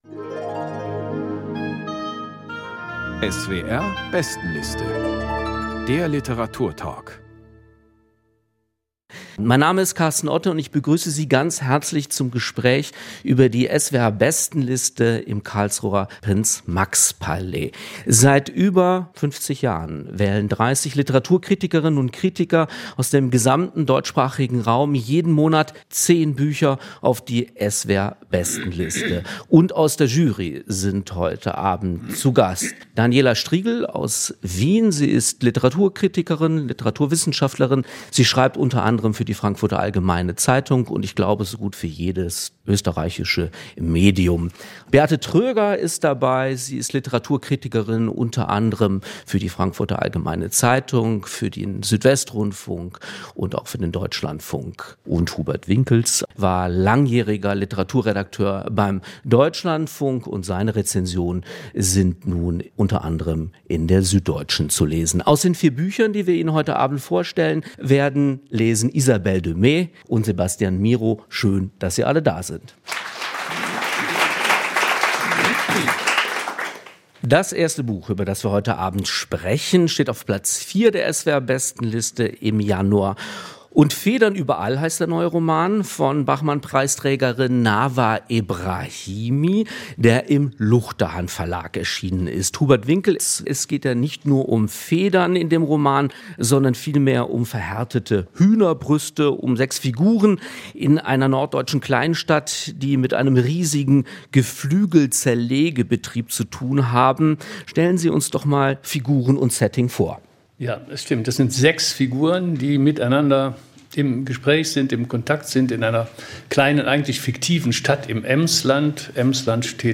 diskutierten im Karlsruher Prinz-Max-Palais vier auf der SWR Bestenliste im Januar verzeichnete Werke.